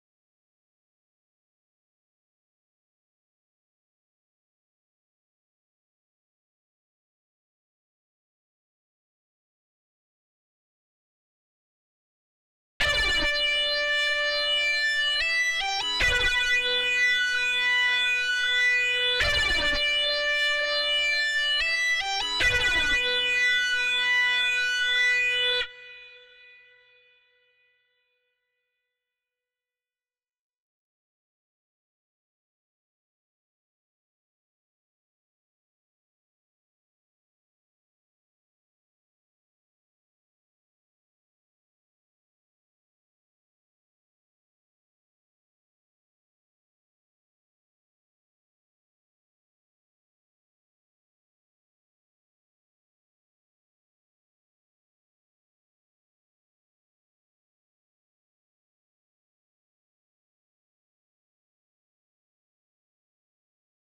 🔹 52 Premium Serum Presets built for synthwave, retro pop, and nostalgic melodic house.
Glowing Leads – Sparkling melodies with an emotional, cinematic edge